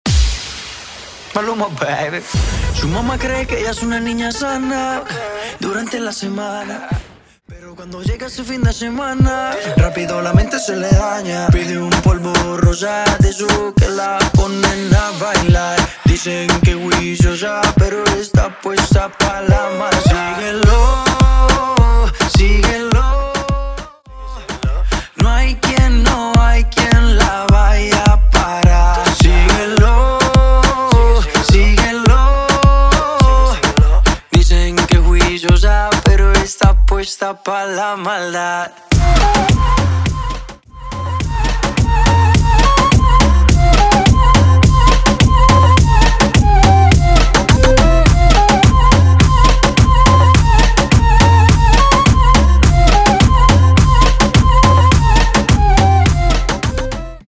Specializing in Latin genres